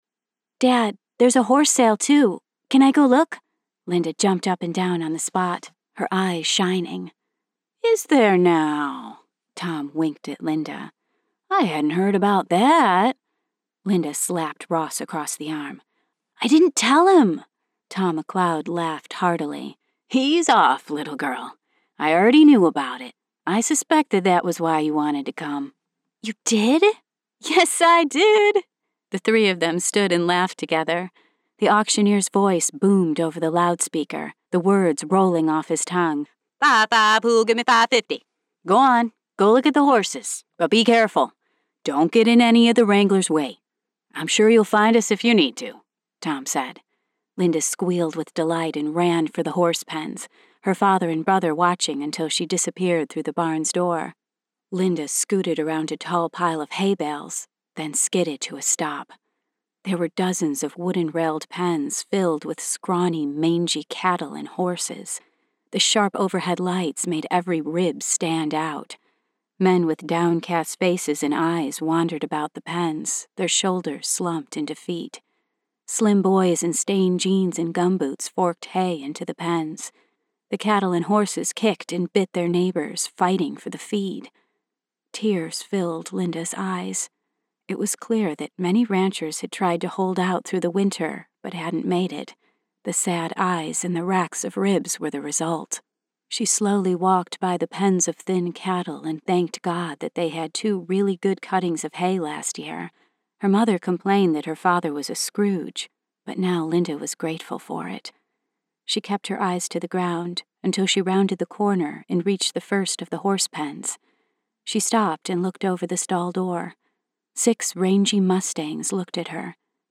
The following audio books are available worldwide on Audible